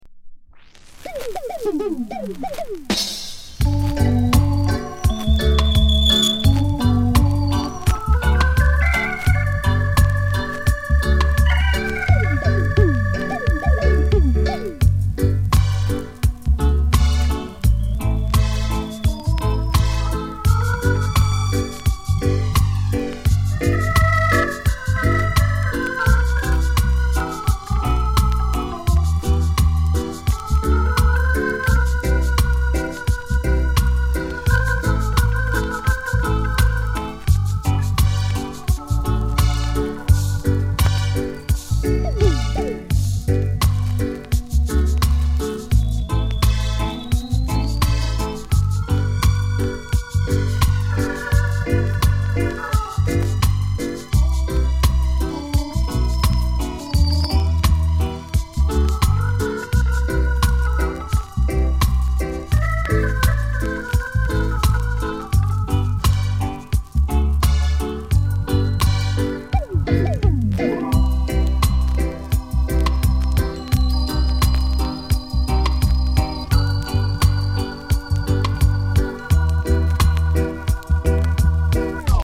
類別 雷鬼